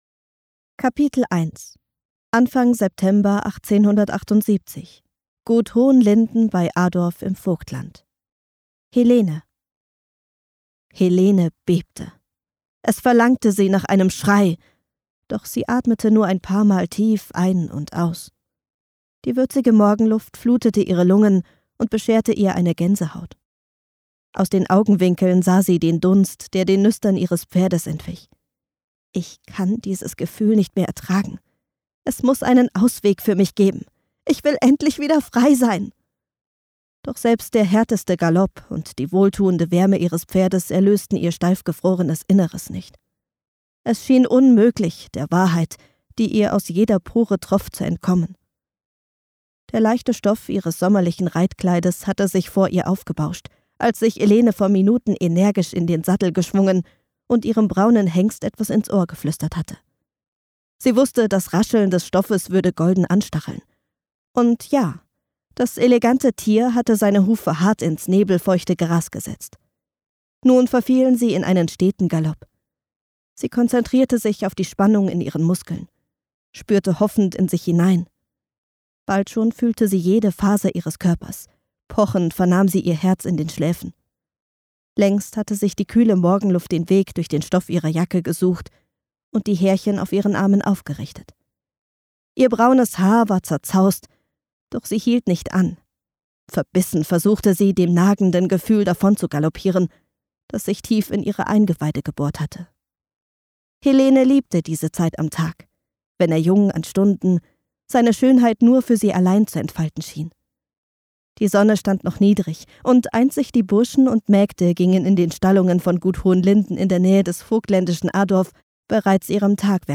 Willkommen in der Hörbuch Welt!
Dadurch entsteht ein intensives Hörerlebnis, das Nähe schafft, ohne zu dramatisieren.